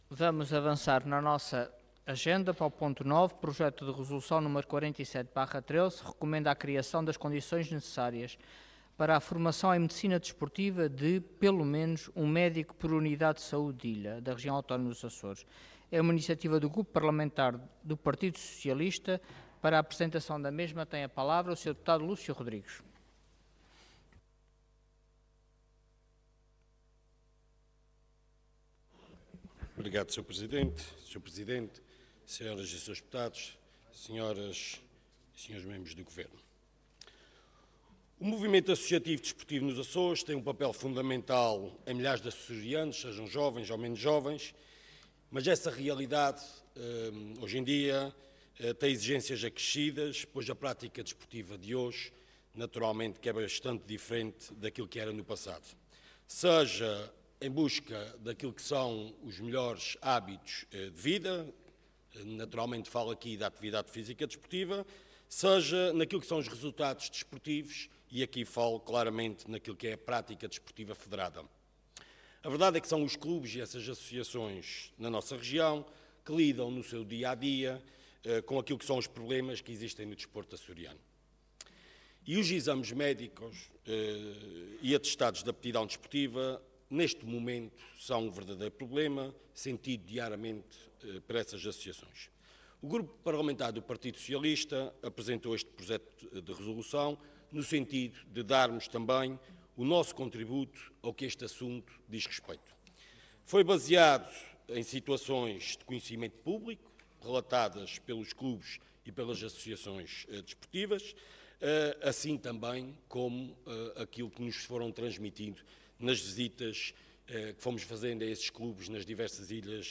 Orador Lúcio Rodrigues Cargo Deputado Entidade PS